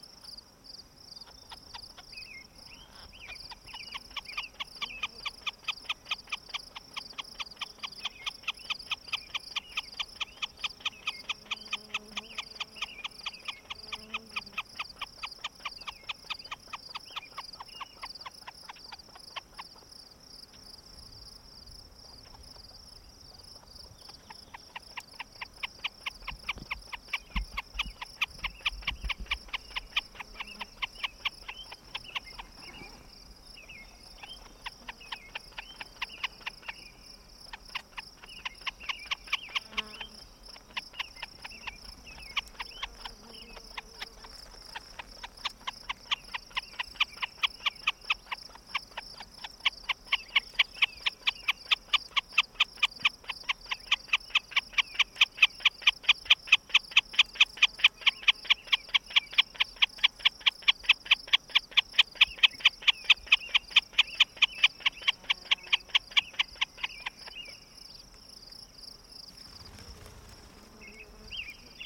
Wilson Snipe Distracting Display
It then walked through the tall grass towards us making this alarm call and when in site we could see the tail was fanned and wings dropped in a distracting display to lure us from the nest toward her. At the end of the recording, you can hear the wings flap as it flys off into the sky! The photo is of the habitat -- a beautiful meadow along the northern coral fence west of the old shepherd's shack.